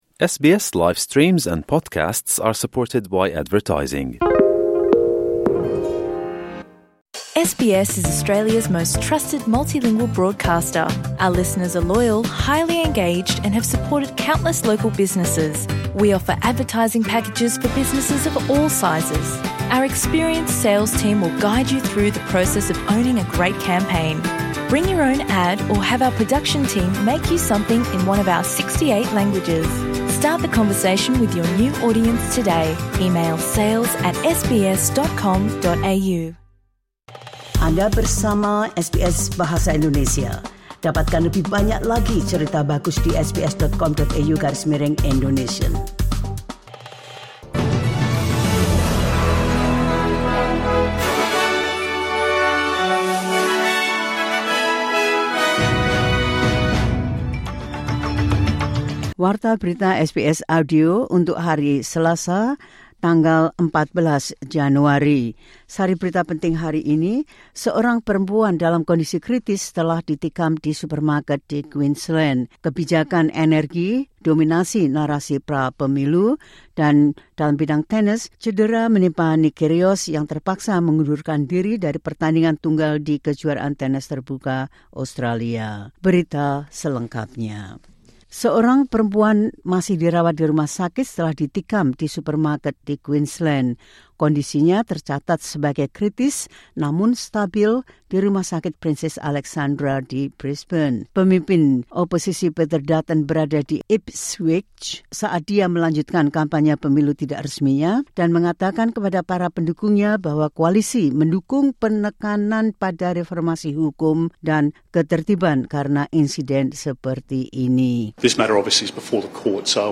Berita terkini SBS Audio Program Bahasa Indonesia – 14 Januari 2025.